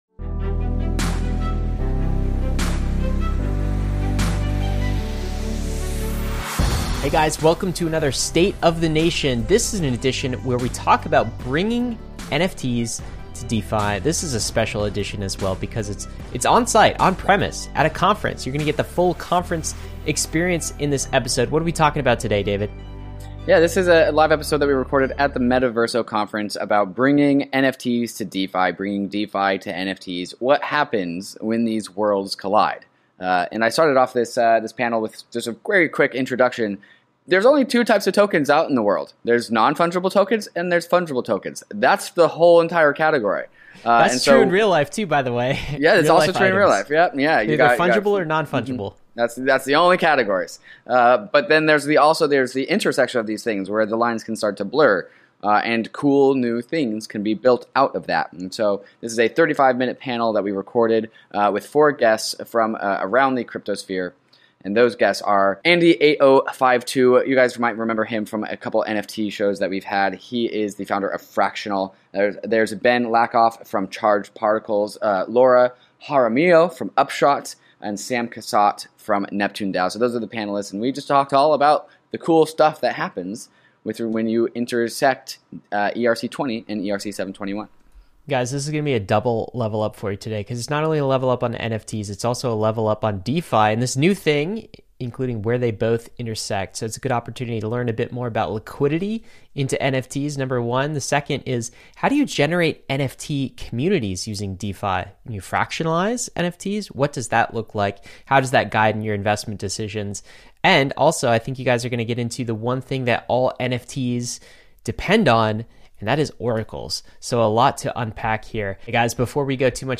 Bringing DeFi to NFTs | Metaverso Panel
On December 7, 2021, Metaverso was held in Puerto Rico as a 1-day conference filled with leaders in crypto culture.